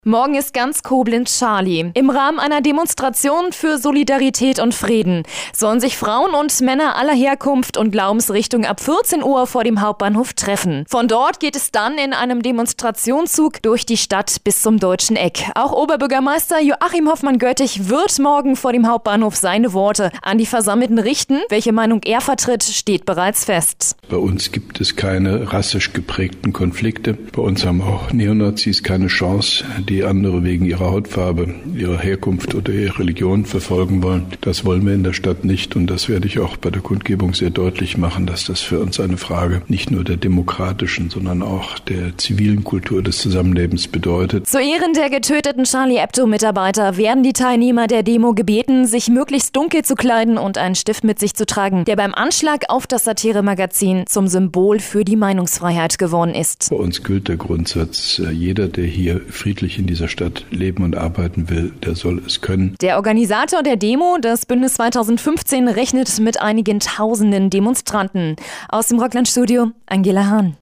Mit Interview des Koblenzer OB Hofmann-Göttig